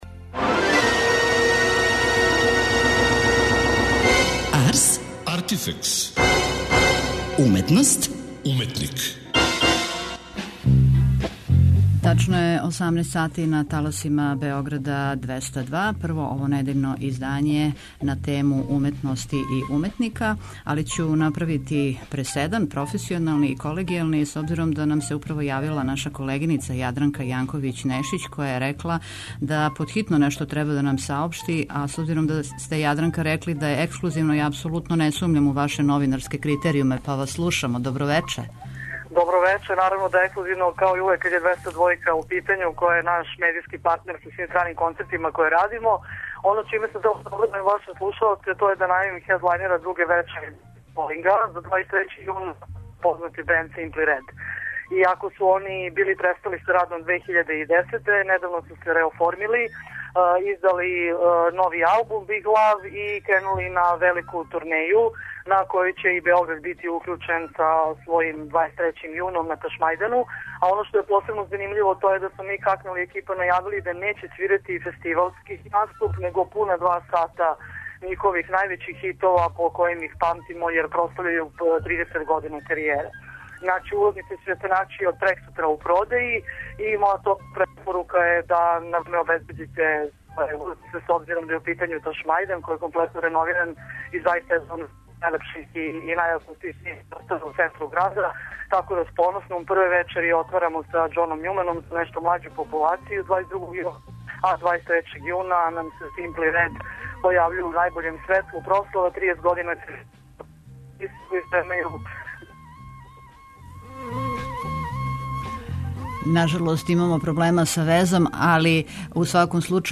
Карановићева и глуми у филму, и то главну улогу, што је довољно разлога за гостовање у данашњој емисији. Уз ову филмску тему, бавимо се и Регионалним конкурсом који је модна организација БАФЕ расписала за младе модне дизајнере.